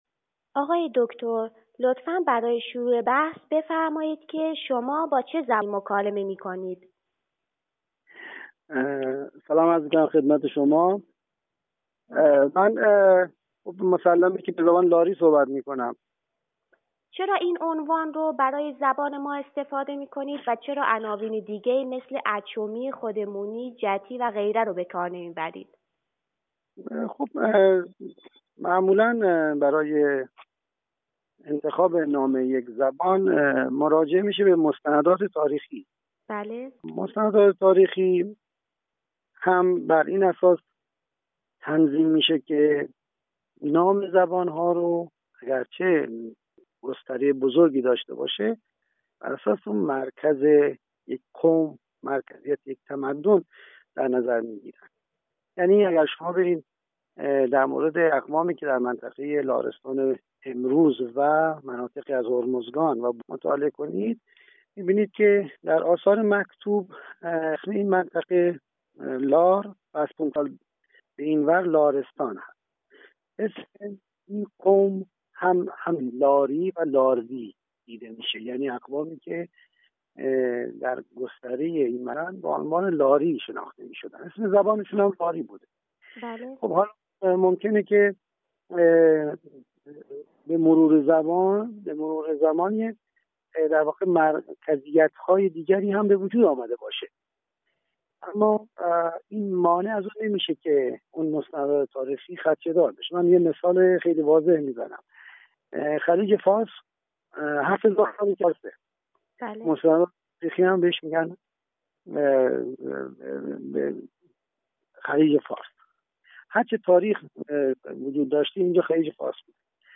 در این مصاحبه بر وجود قومی به نام «لاری» یا «لاروی» در مناطق جنوب تا سواحل هند تاکید و این ادعا را بازگو می‌کند که نام زبان رایج در این مناطق از نام این قوم گرفته شده است.